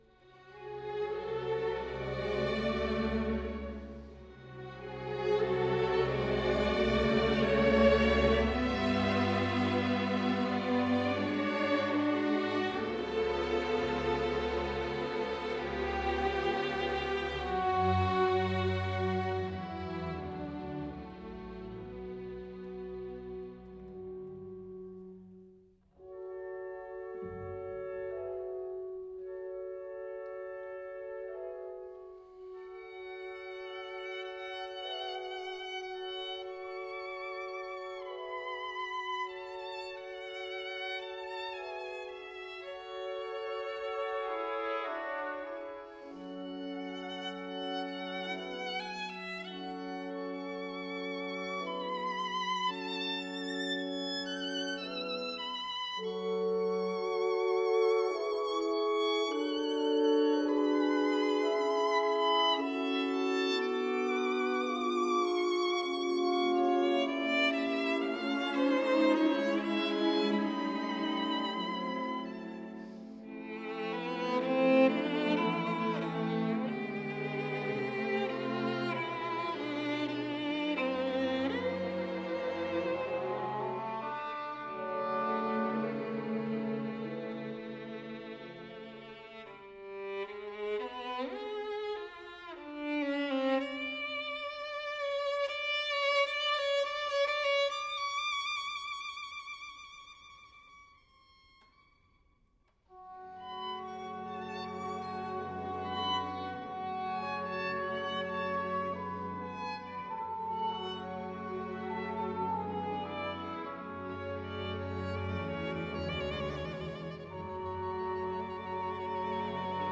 Paul Juon: Violin Concerto No.2, In A Major, Op. 49 – II. Elegie “Weisse Nächte”, Andantino